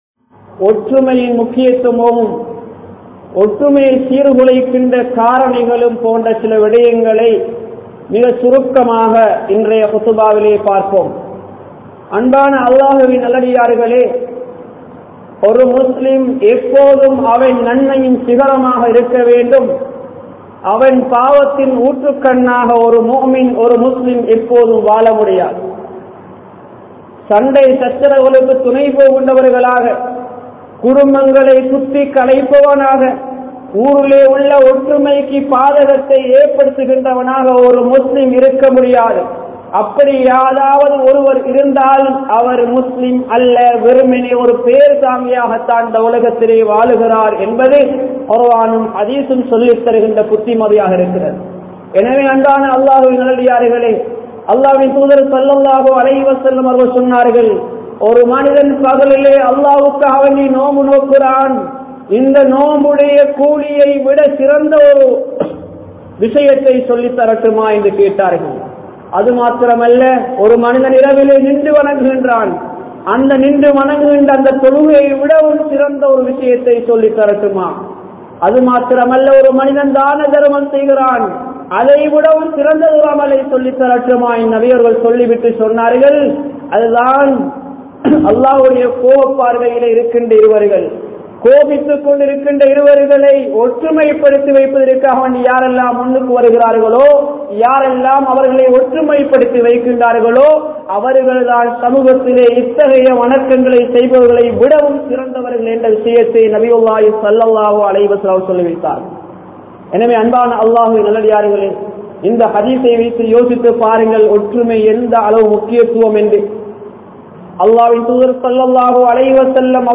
Ottrumaiyai Seerkulaikkum Kaaranihal (ஒற்றுமையை சீர்குழைக்கும் காரணிகள்) | Audio Bayans | All Ceylon Muslim Youth Community | Addalaichenai
Kurunegala, Kekunugolla, Pothuhara Jumua Masjidh